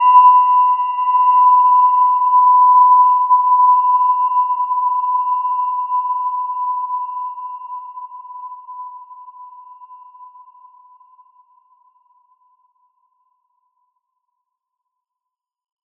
Gentle-Metallic-4-B5-mf.wav